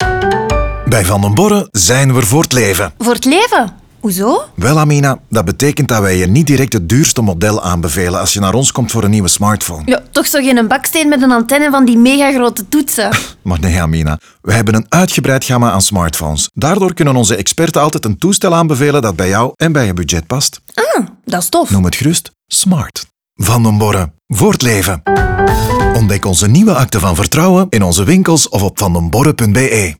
Zo gaat de voice-over telkens in dialoog met verschillende klanten en helpt hij hen met al hun vragen.
Last but not least kreeg ook het soundlogo een lichte make-over.